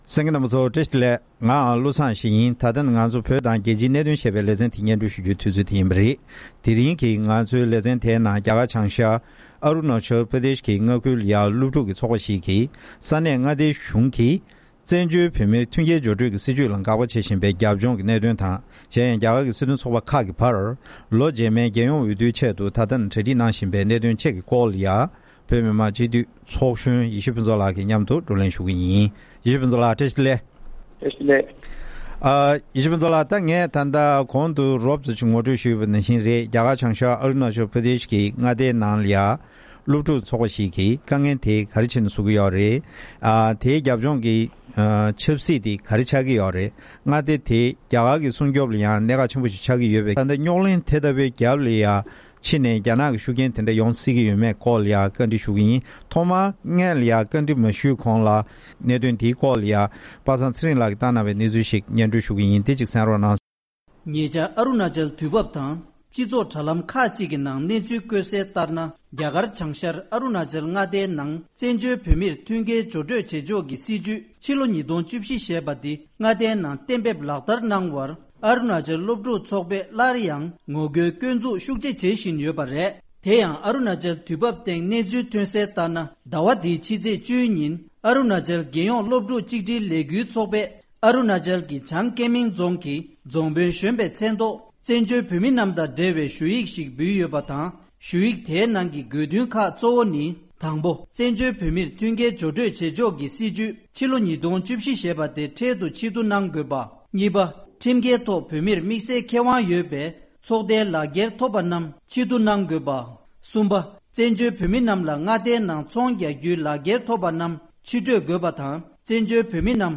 བགྲོ་གླེང་